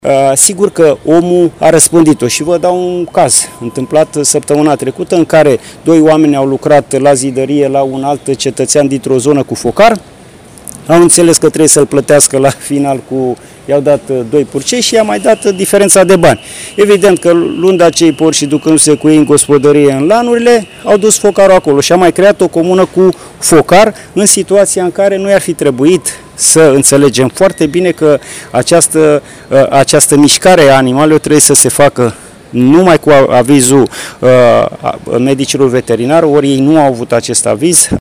Peste 11.000 de oameni dislocati in fiecare zi pentru a preveni răspândirea pestei porcine . Declarația a fost făcută la iași de secretarul de stat la ministerul agriculturii, Daniel Botănoiu, Acesta a arătat cu degetul către oameni